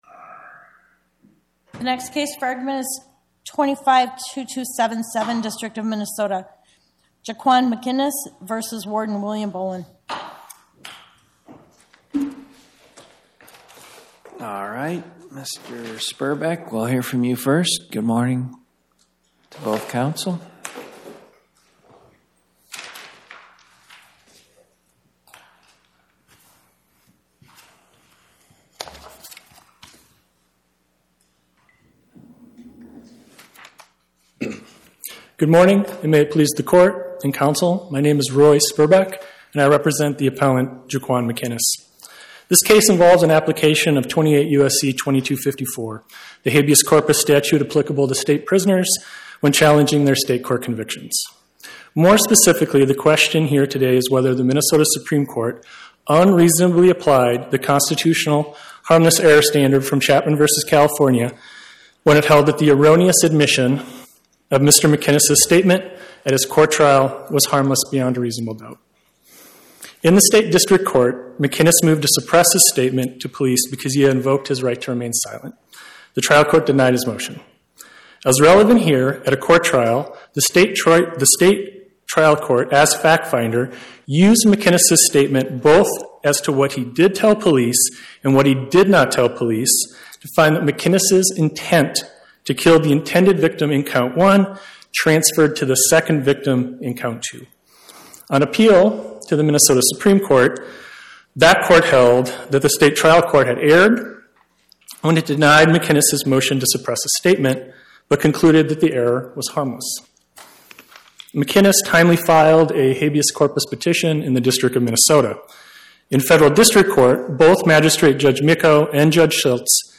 Oral argument argued before the Eighth Circuit U.S. Court of Appeals on or about 03/19/2026